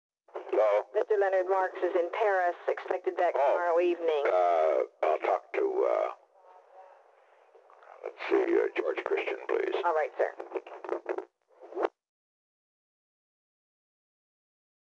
Location: White House Telephone
The White House operator talked with the President.